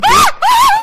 myrrscream.mp3